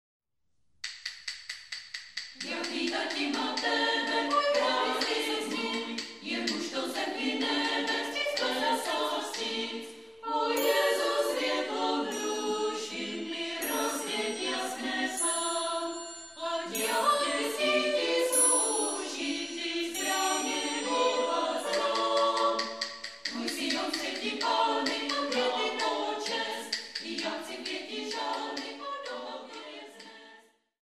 Komorní pěvecké sdružení Ambrosius
Demo nahrávka, prosinec 2000